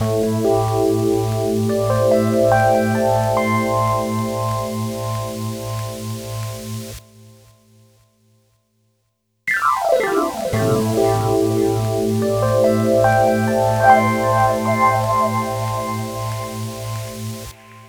Ala Brzl 2 Rhodez-A.wav